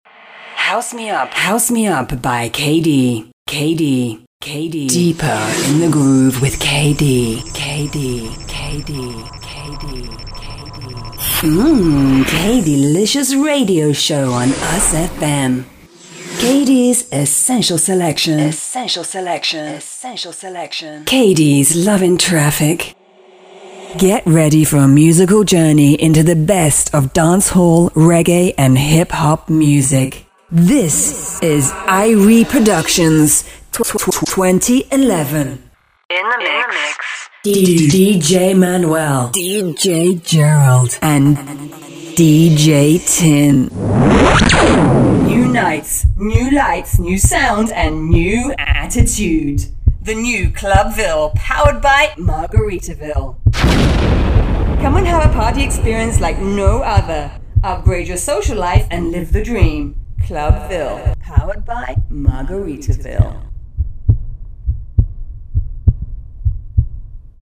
British Female Voice Over Radio Imaging Demo
Voz Jovem 01:07
- Broadcast quality recording from her professional home studio